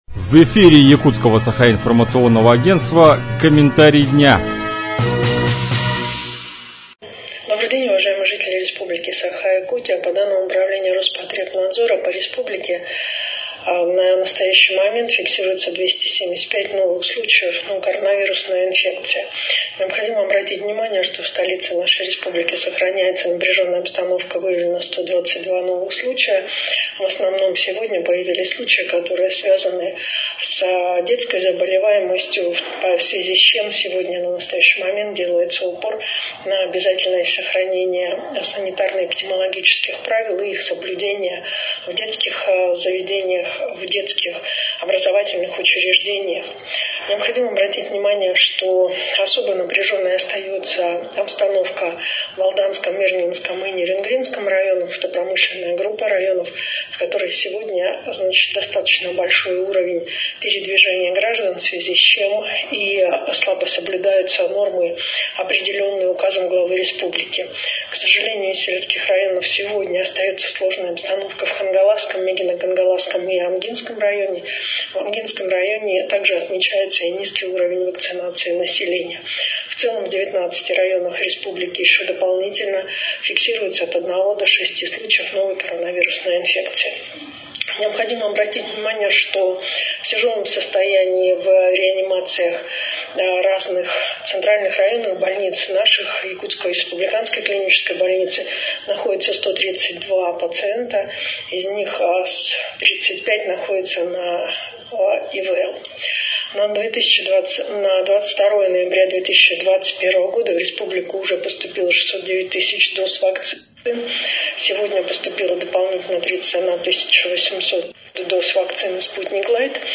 Подробнее в аудиокомментарии заместителя руководителя республиканского оперштаба, вице-премьера Якутии Ольги Балабкиной: